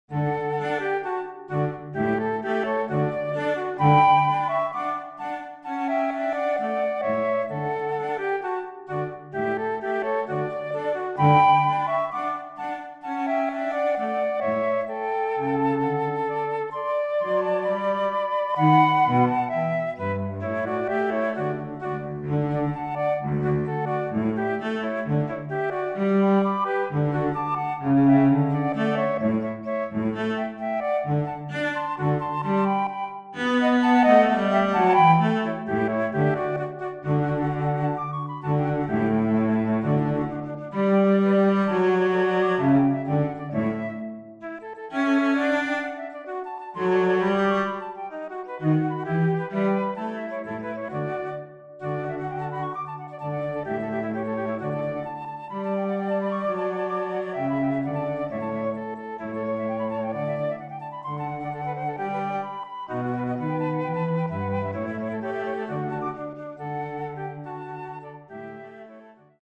6 Trios für 2 Flöten und Violoncello in Vorbereitung